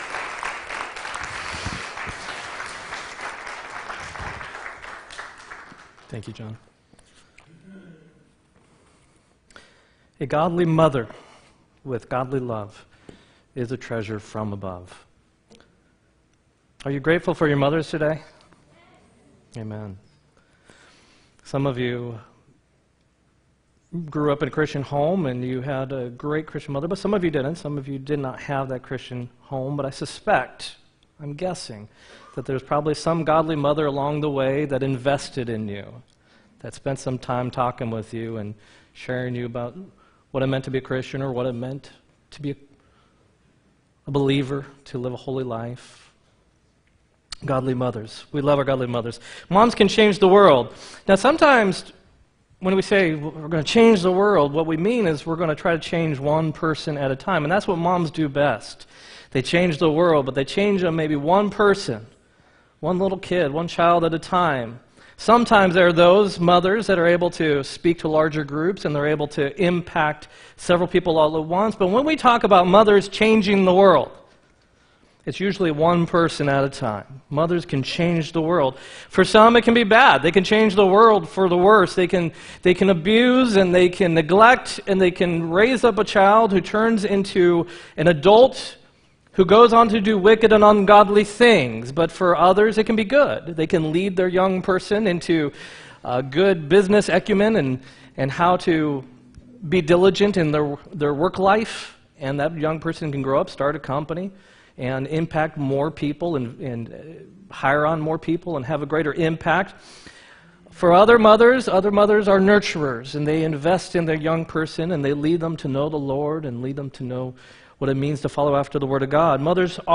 5-5-18 sermon
5-5-18-sermon.m4a